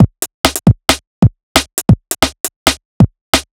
Killa Break 2 135.wav